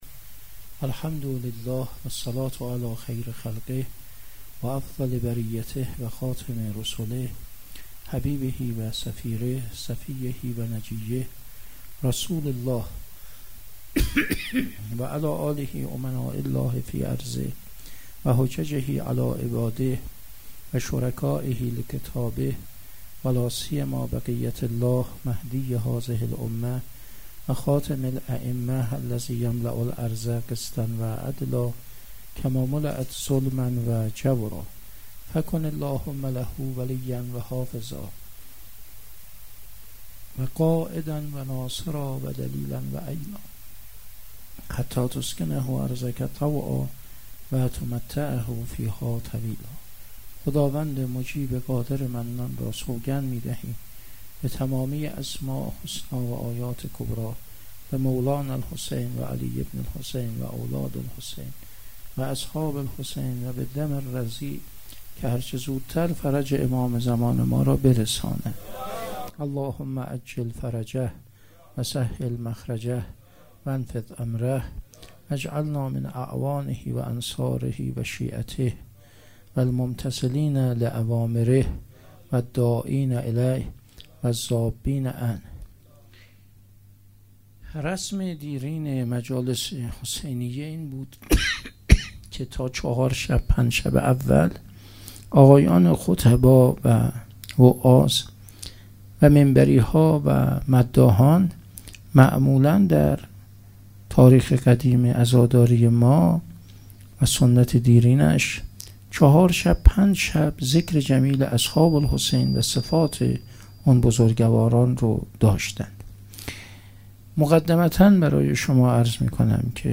دهه اول محرم - شب دوم - سخنرانی